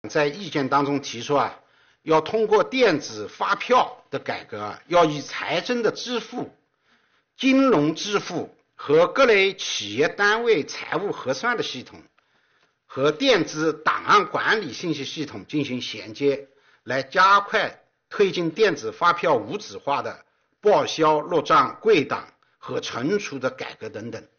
近日，国务院新闻办公室举行新闻发布会，国家税务总局相关负责人介绍《关于进一步深化税收征管改革的意见》（以下简称《意见》）有关情况。会上，国家税务总局党委委员、副局长任荣发表示，税收关乎千万家，涉及方方面面，税收发展靠大家。